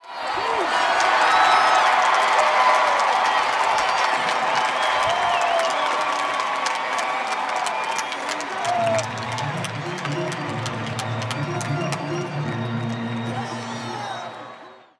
crowd-cheer-organ.wav